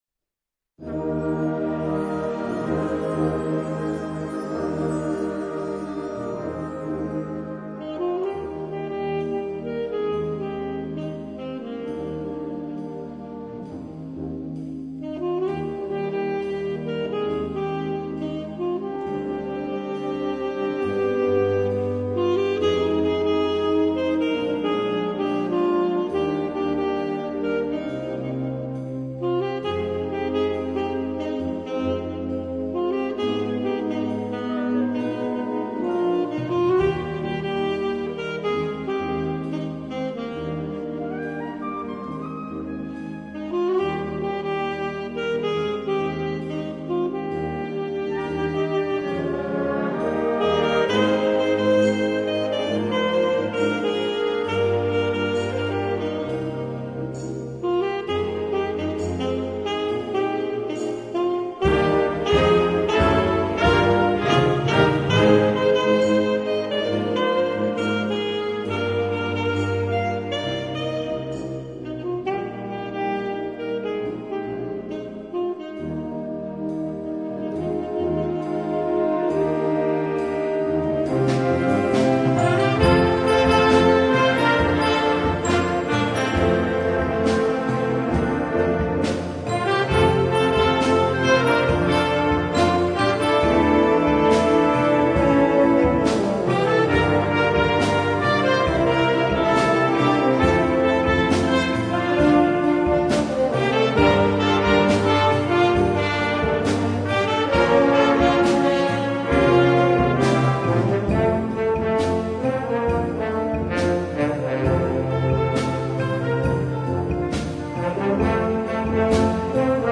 per solista e banda